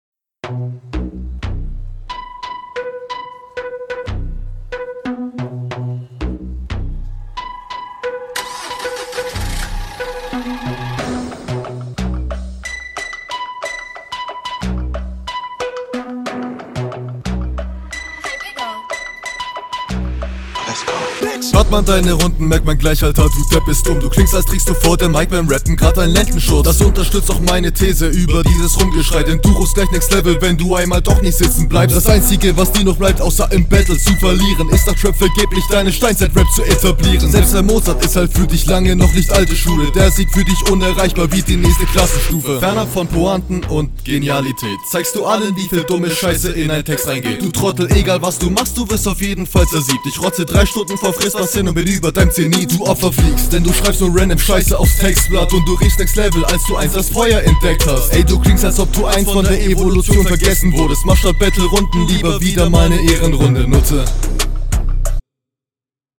Finde dich auf diesem Beat leider etwas zu unsicher, dein Flow ist oft abgehakt.